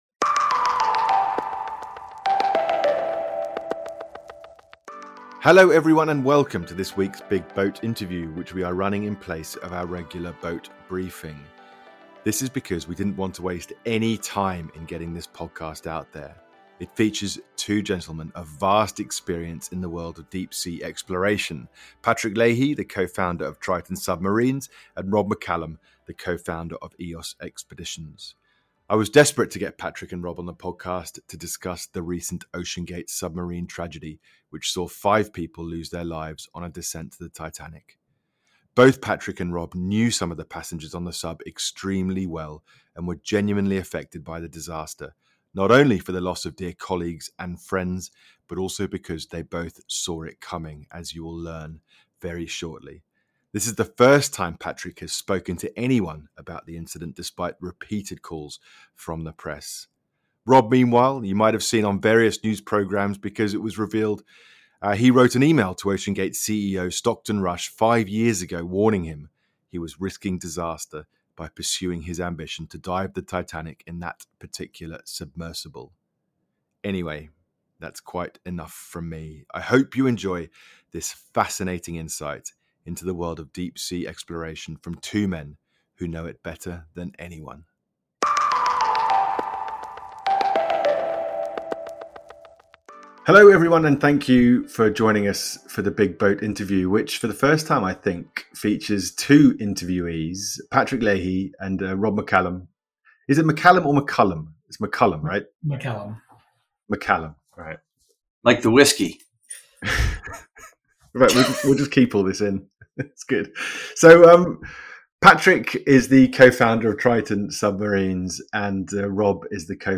171: The Big BOAT Interview Special: understanding the OceanGate submersible tragedy